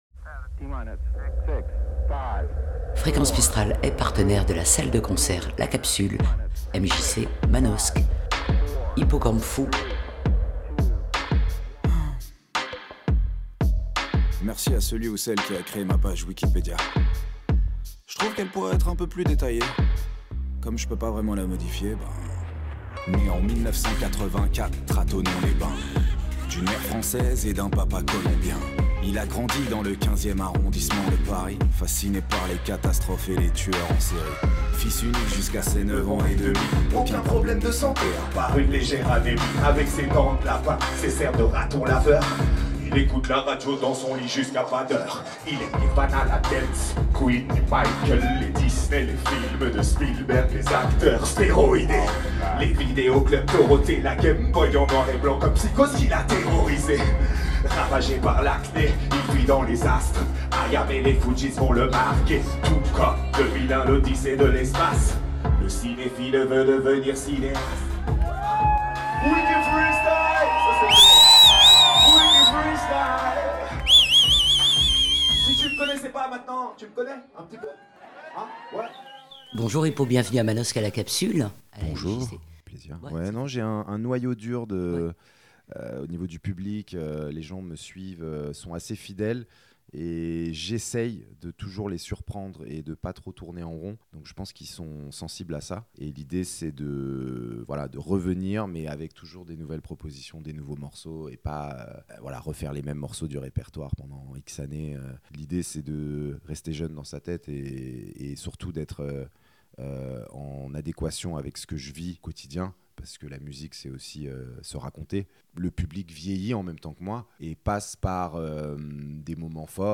Rencontre avec Sébastien Gonzalez, fondateur du groupe.